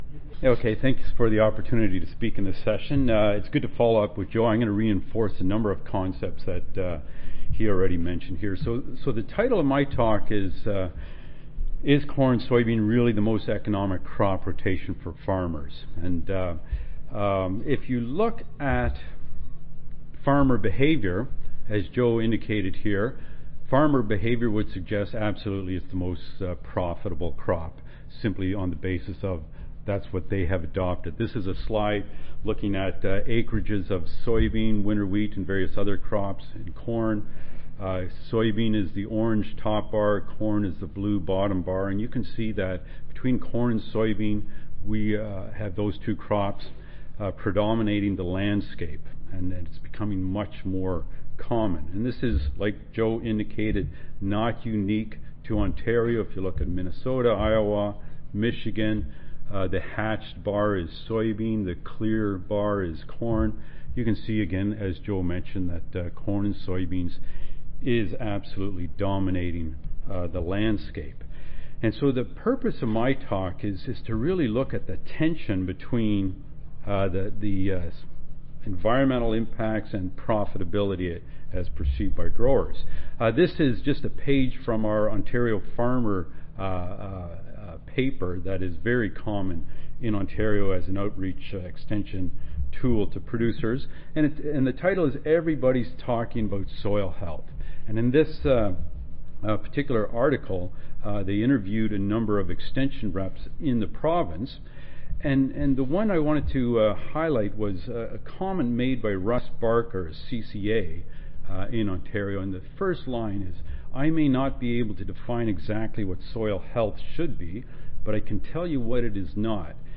See more from this Division: C03 Crop Ecology, Management and Quality See more from this Session: Symposium--Economic Viability of Short- Vs Long-Term Cropping Systems